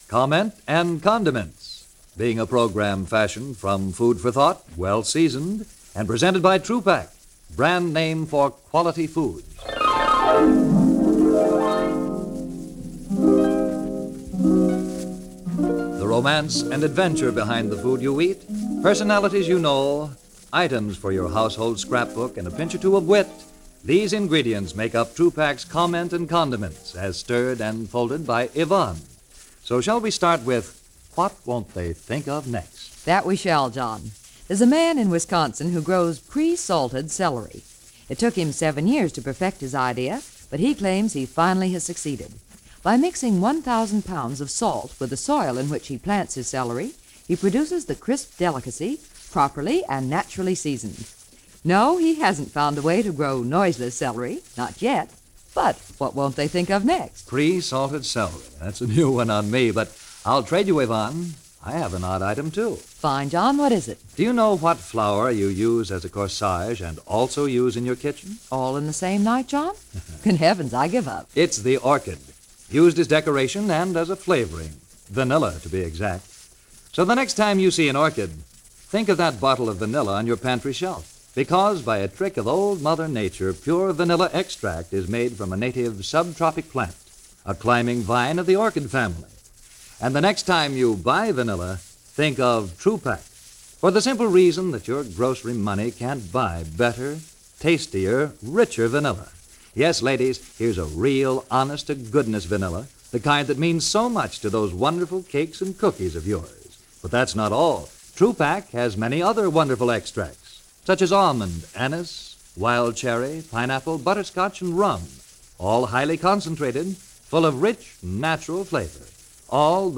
On this particular broadcast, a “food and commentary” program Comments And Condiments, the subject of Pig 311 comes up.
ABC-Radio-Comments-and-Condiments-September-1946.mp3